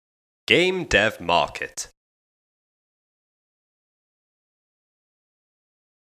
You can change the looping interval of the watermark as you see fit to best protect your audio asset.
GDMAudioWatermark.mp3